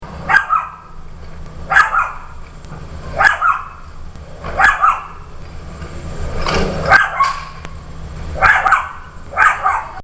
chihuahua barking redrumI live in an ideal location for haunting inspiration.
What’s interesting, is that when it really gets going it sounds like it’s saying, “Redrum! Redrum!”